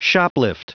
Prononciation du mot shoplift en anglais (fichier audio)
Prononciation du mot : shoplift